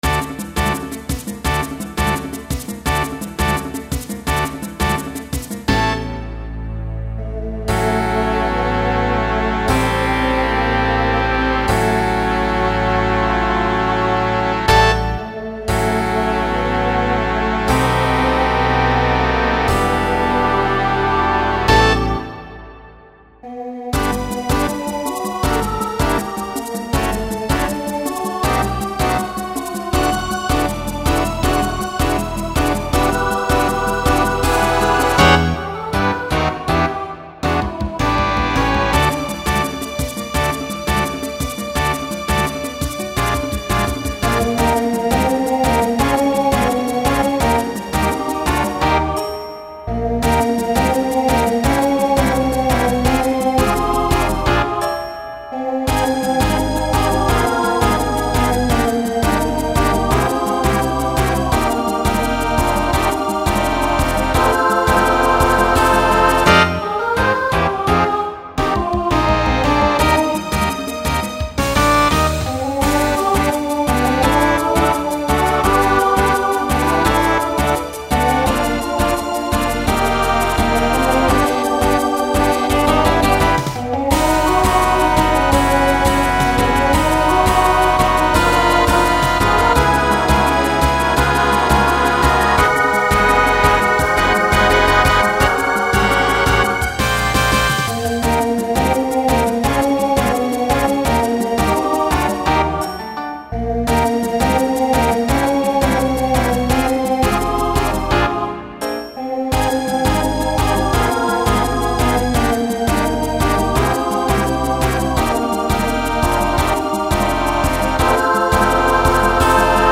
Genre Broadway/Film
Ballad Voicing SSA